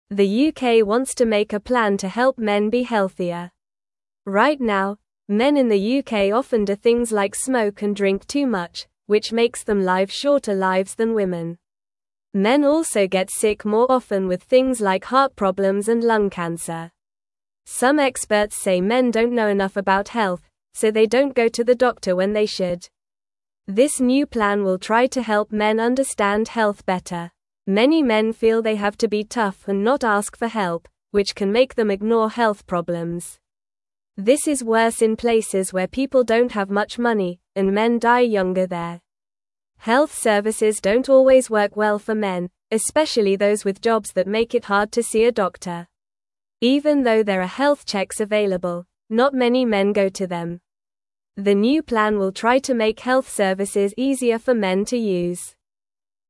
Normal
English-Newsroom-Beginner-NORMAL-Reading-Helping-Men-Be-Healthier-in-the-UK.mp3